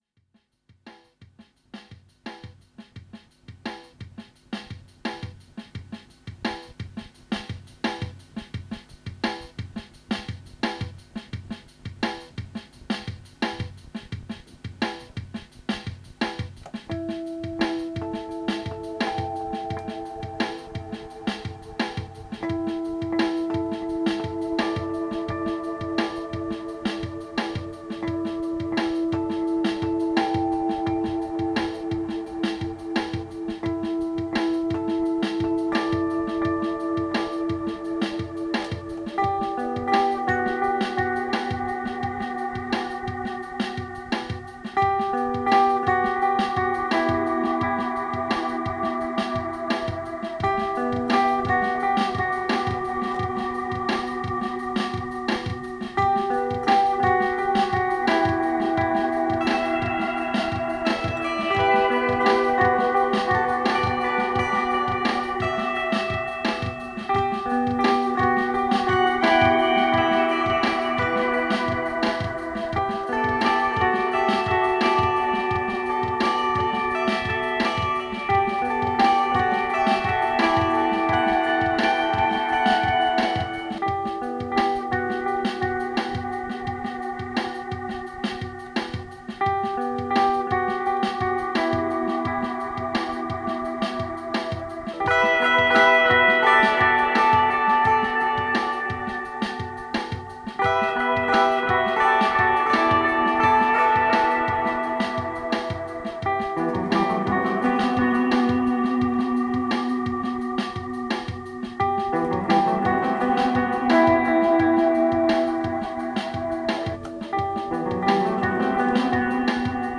Gatunek: muzyka instrumentalna